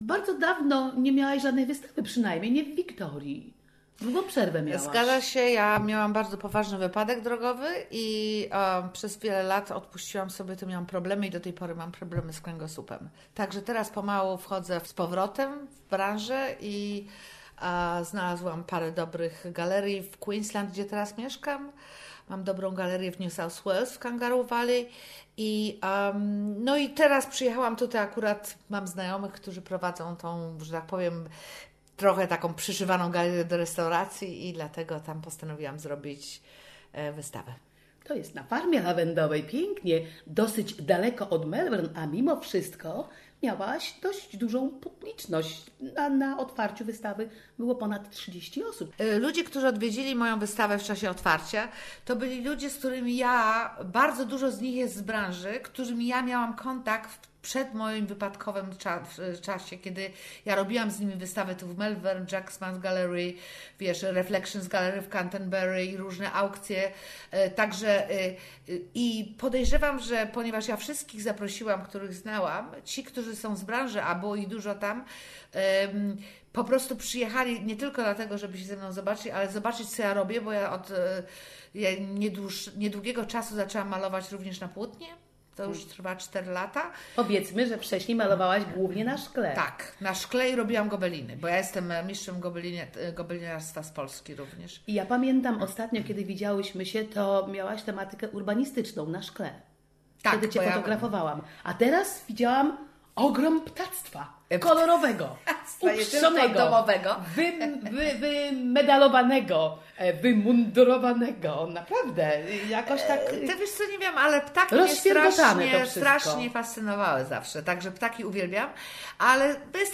has a chat with an artist